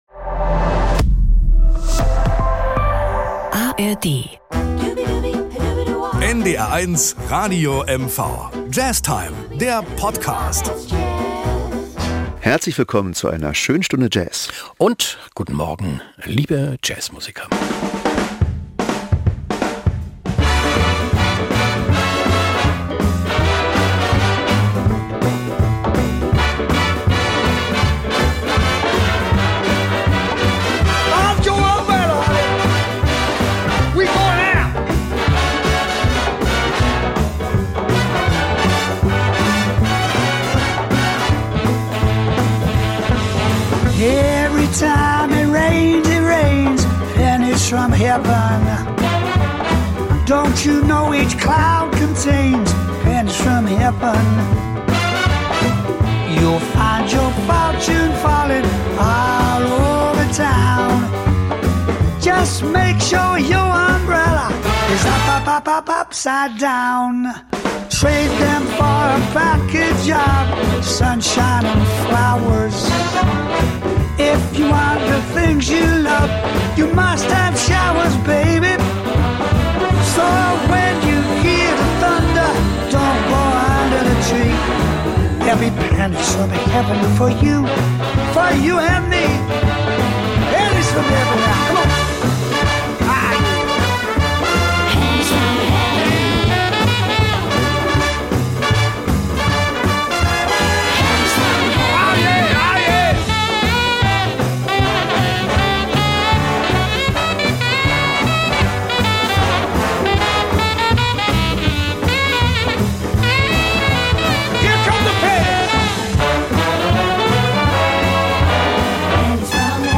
Das LIVE - Anspiel ist : „Gentle Rain” – ein Bossa Nova ("Chuva Delicada"), 1965 komponiert von Luiz Bonfá, Folgende Titel sind zu hören: 1.